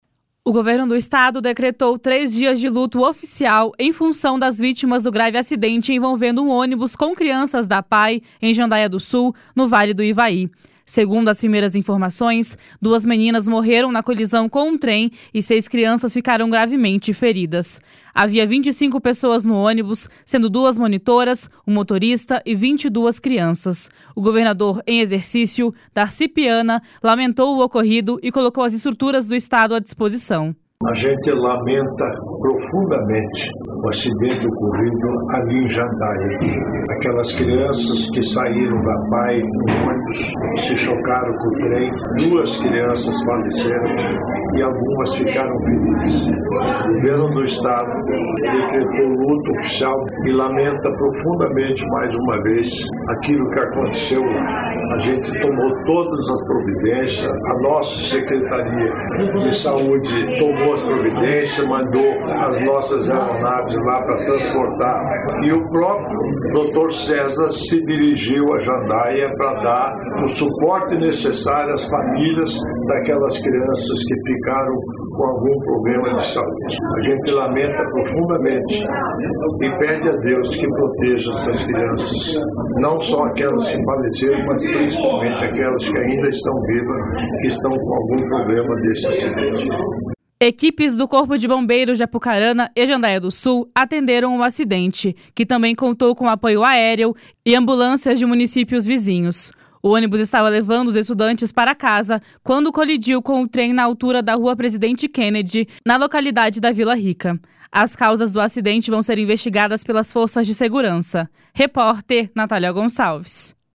O governador em exercício, Darci Piana, lamentou o ocorrido e colocou as estruturas do estado à disposição. // SONORA DARCI PIANA //